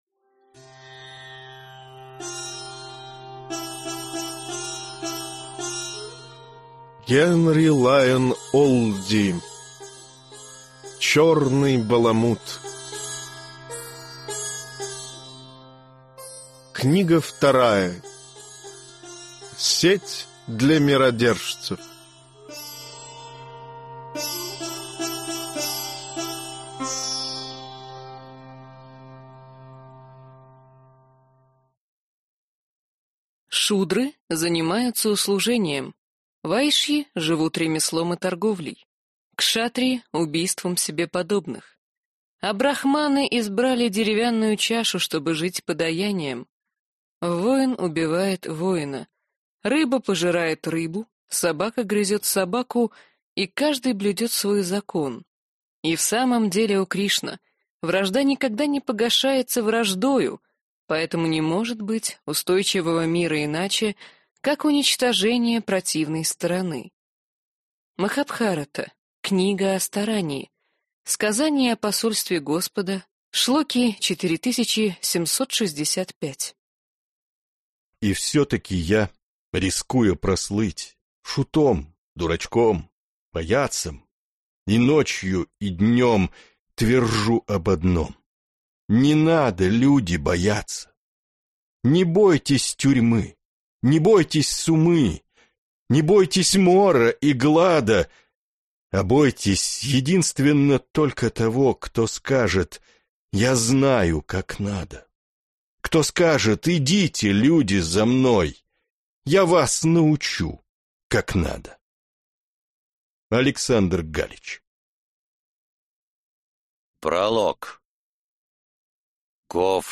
Аудиокнига Сеть для Миродержцев | Библиотека аудиокниг